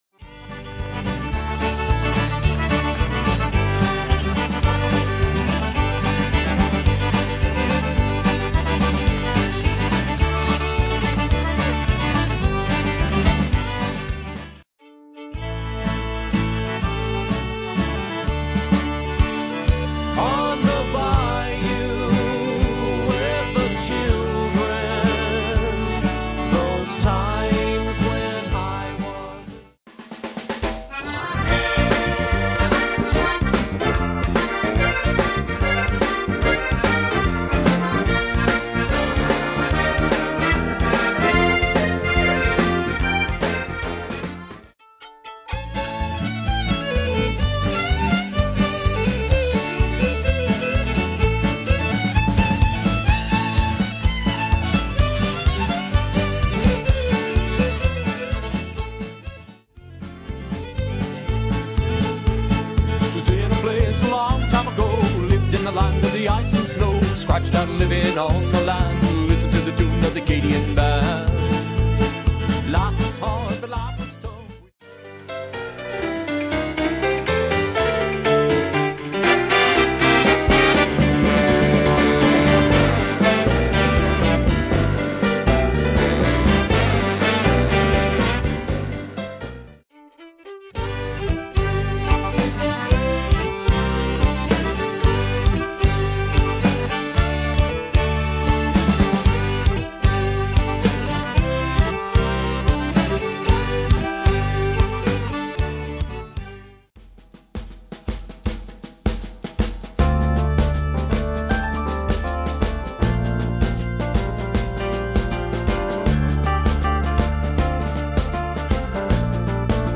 sets a party tone for any event. Instrumental.